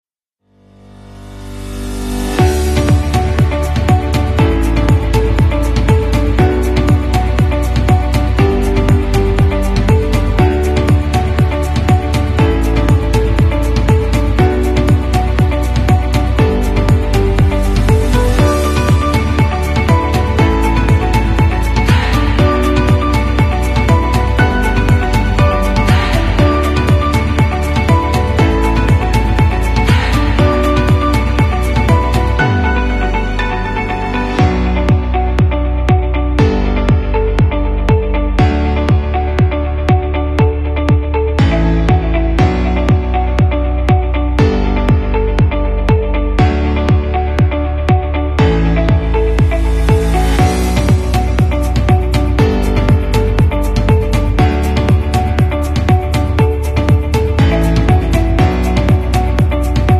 Trying out the new subway in Saigon Vietnsm